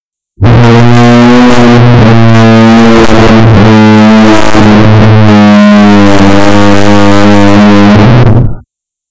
cancertrombone.mp3